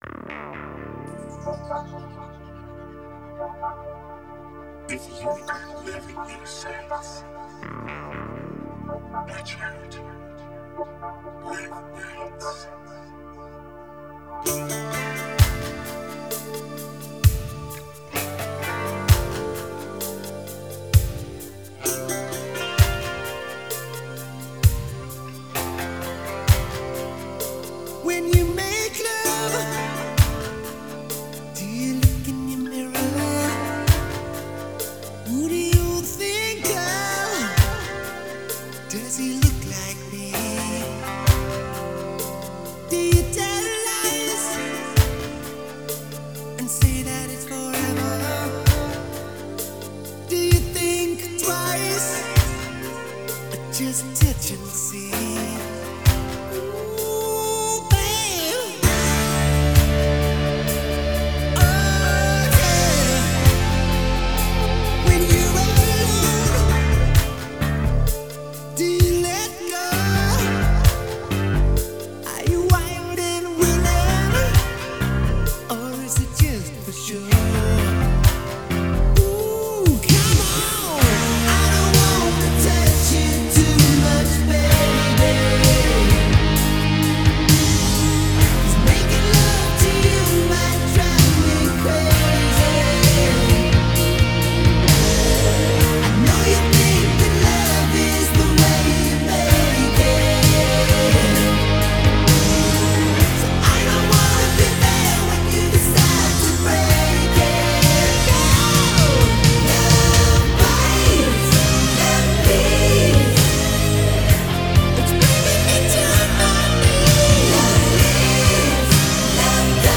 Жанры: хард-рок, глэм-метал, хеви-метал, поп-рок